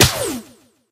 gene_hit_01.ogg